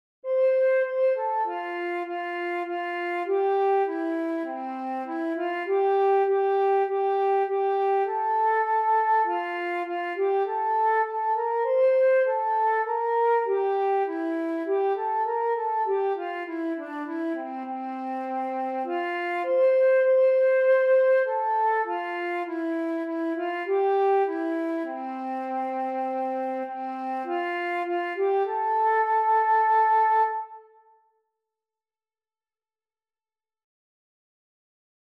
Een canon voor de zomer